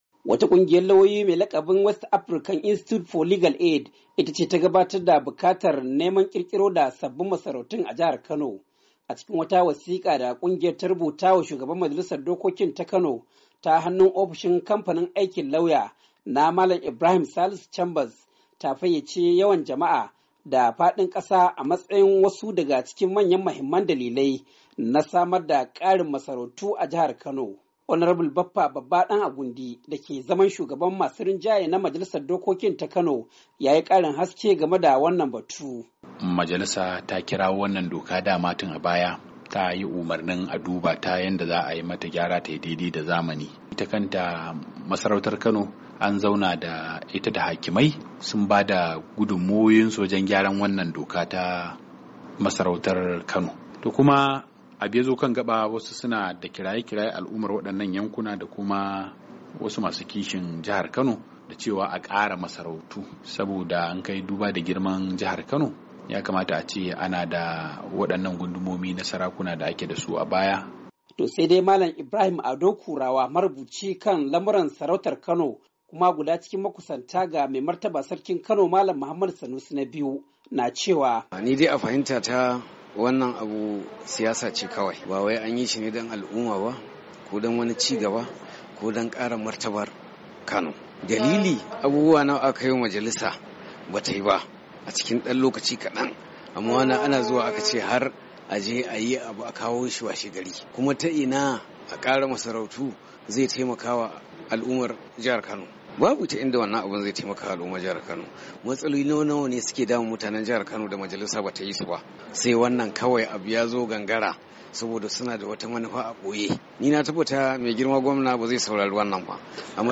Ga karin bayani daga wakilin mu na Kanon